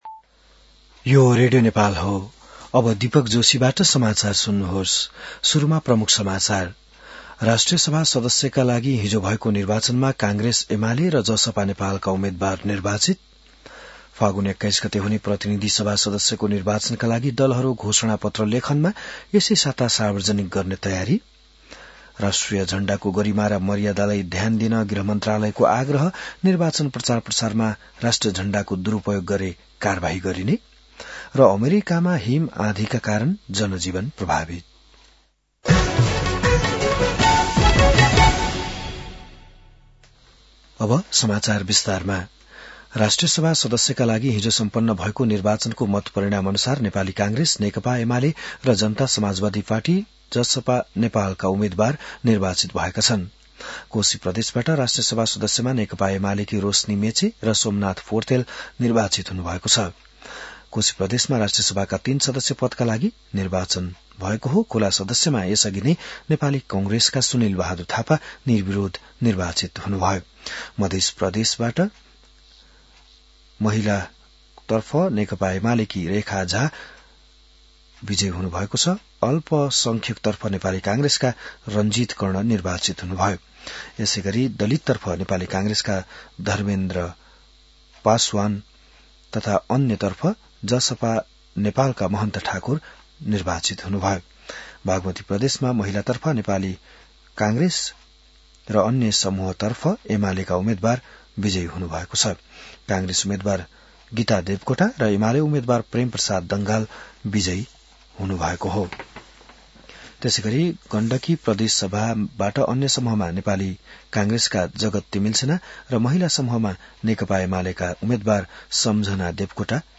बिहान ९ बजेको नेपाली समाचार : १२ माघ , २०८२